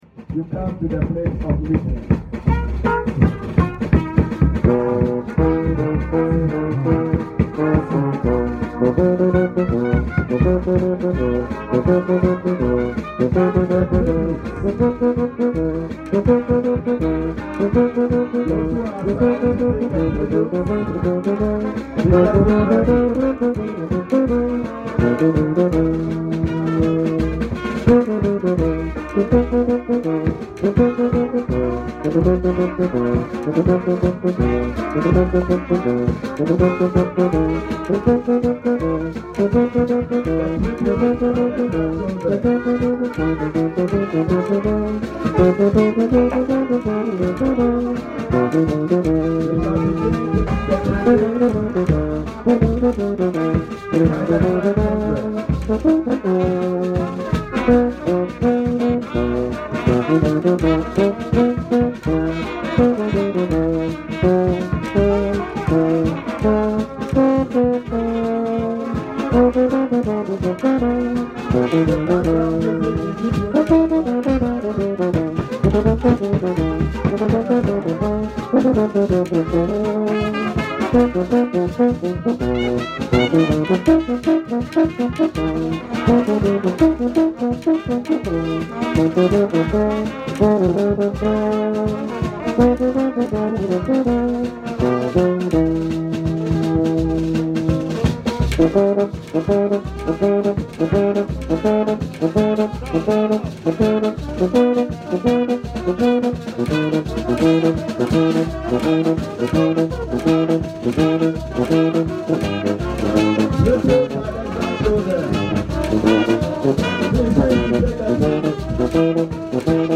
Playing The Euphonium Is Not Sound Effects Free Download